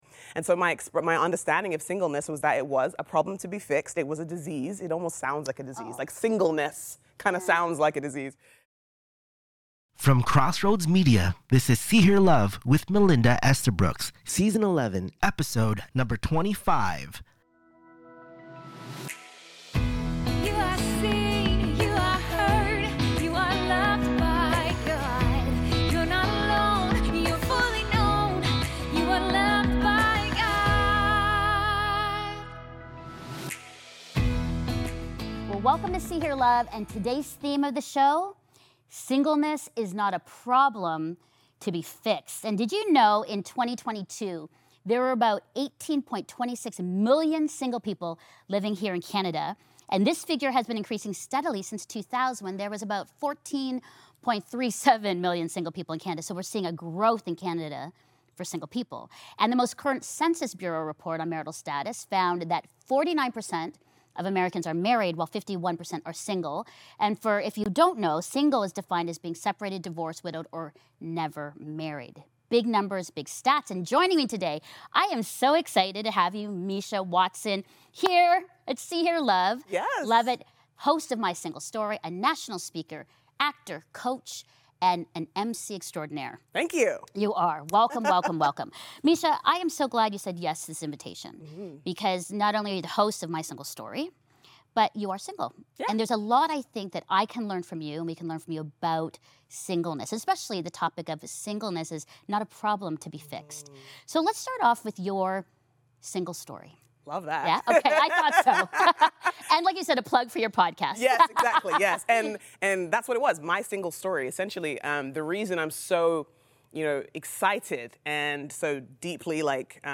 From heartbreak and loneliness to deep intimacy with God, they challenge the idea that singleness is a problem to be fixed and explore the grief, courage, and unexpected beauty of this season. If you have ever felt pitied, overlooked, or pressured by church culture, this conversation will reframe everything.